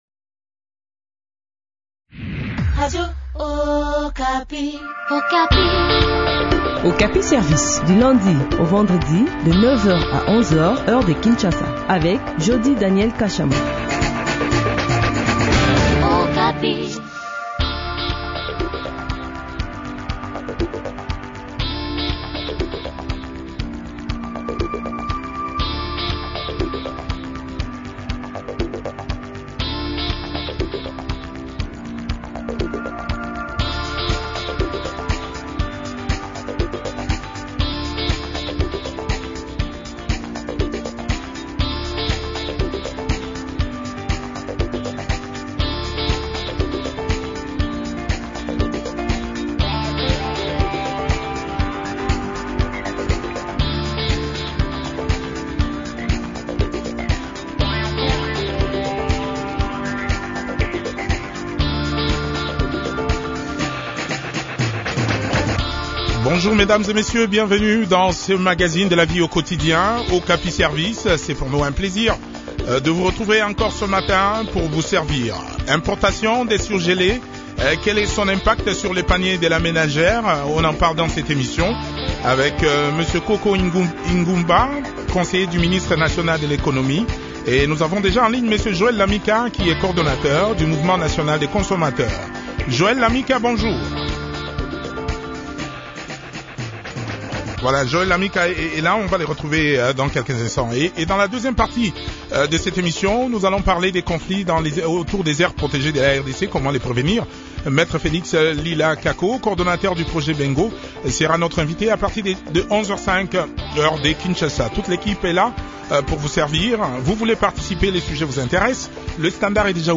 stratège et analyste économique.